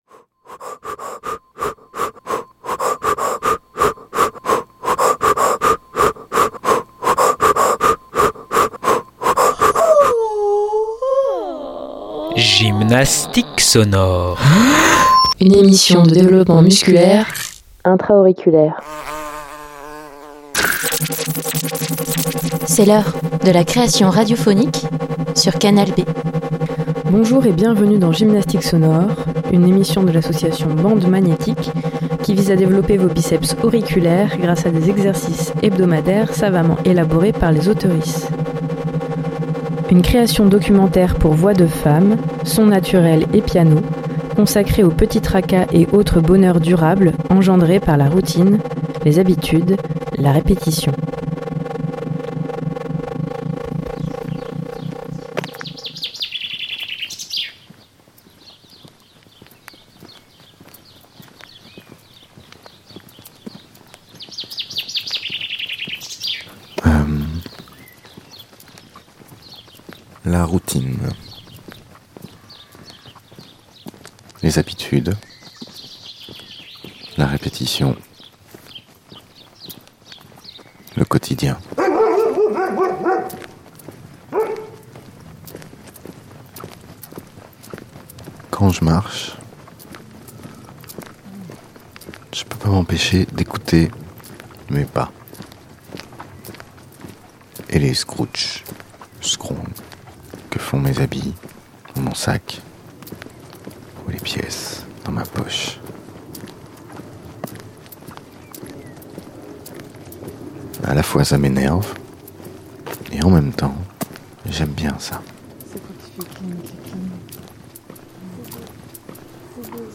Un programme audio-nutritionnel riche en protéines : yaourt sonore, babillages immersifs, chuchotements en 3D binaural, chuintements en 5.1 dolby, stridulations, sons de cloches, fausses notes, virelangues en tout genre…
Les Menstruelles #77 - Naufrage de la peur 13/12/2025 60 mn Les Menstruelles , c'est un collectif de correspondances à voix multiples. Des missives de femmes éparpillées en France et ailleurs.